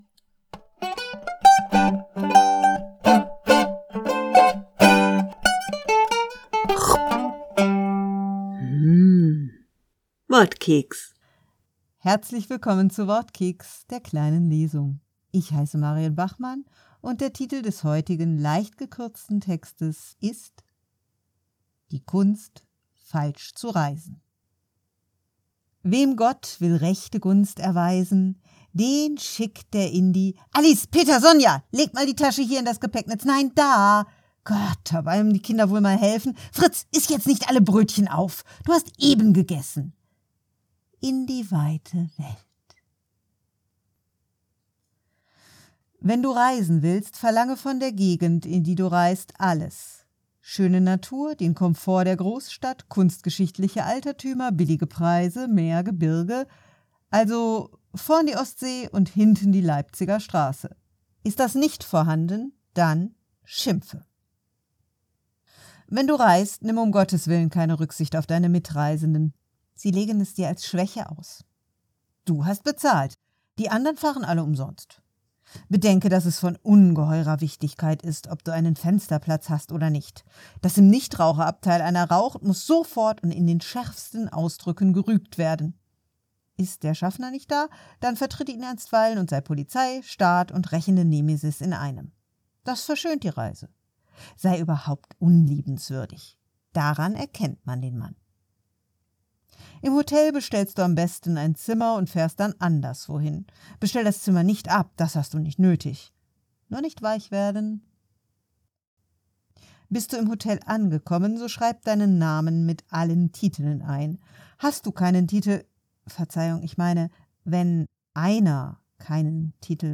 Wortkeks - die kleine Lesung
Kleine Lesung heute aus dem Koffer.